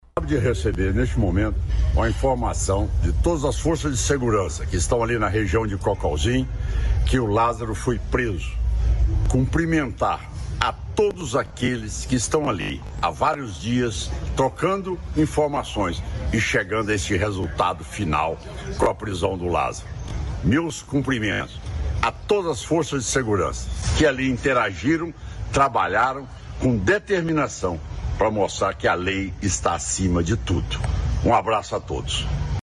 Ronaldo Caiado Governador de Goiás anunciou a prisão em suas redes sociais.